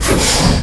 hydraulic_stop02.wav